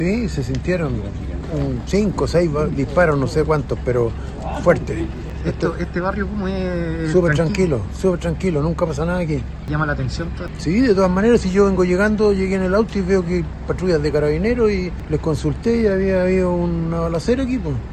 Vecinos del sector relataron los disparos que escucharon durante la mañana, indicando que estaban preocupados, ya que el sector habitualmente es tranquilo.
cuna-vecino-balacera.mp3